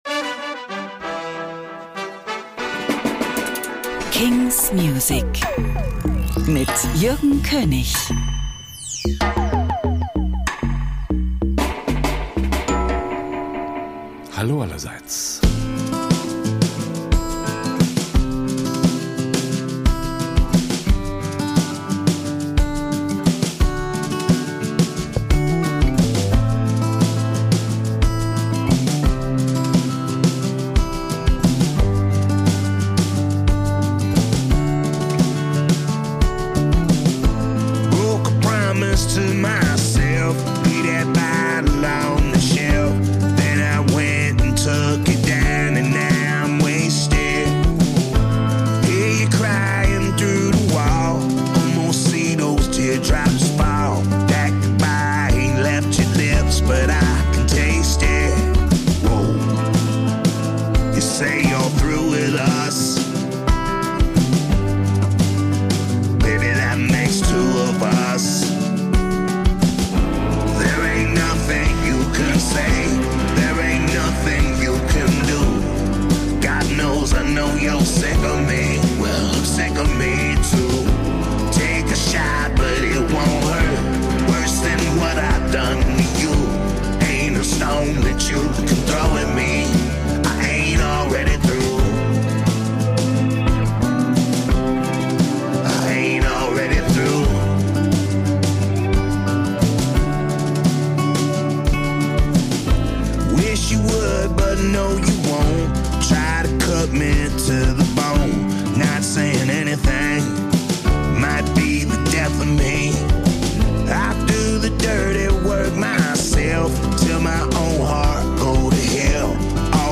indie & alternative releases